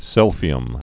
(sĭlfē-əm)